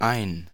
Ääntäminen
IPA: [aɪn]